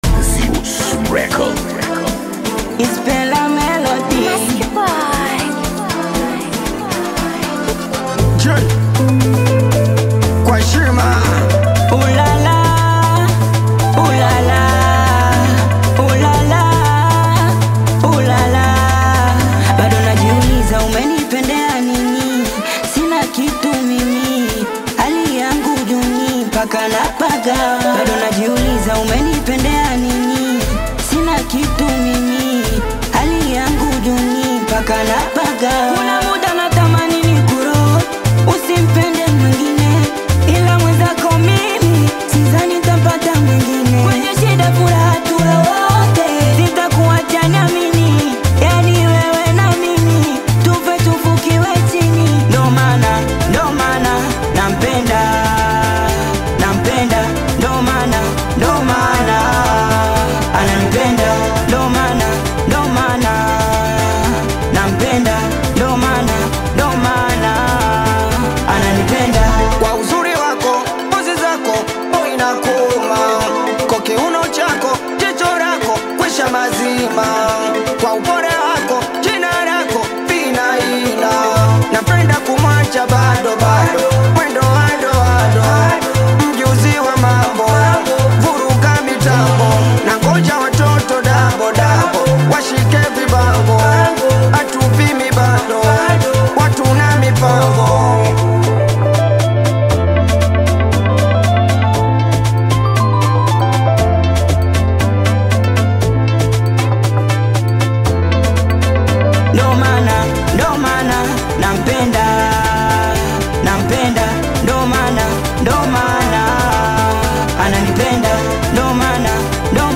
AudioSingeli
feel-good Afro-Beat/Singeli single